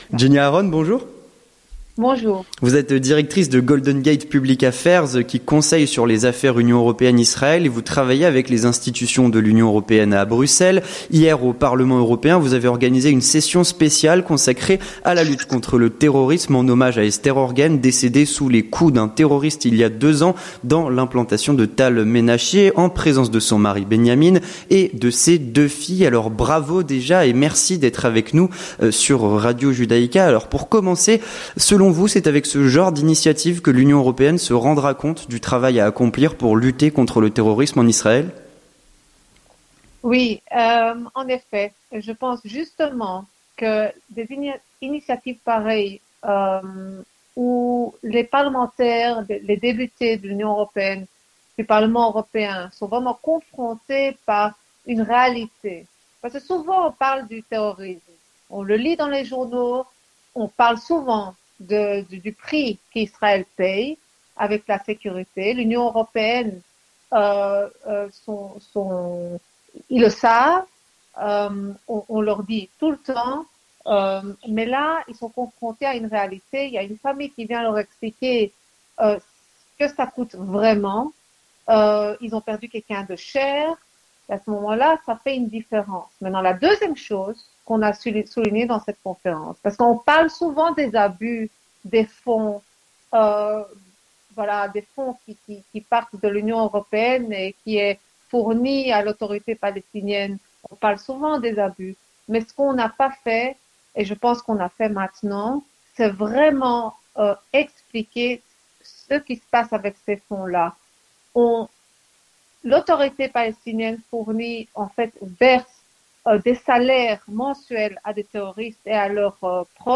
L'Entretien du Grand Journal - relations Union européenne - Israël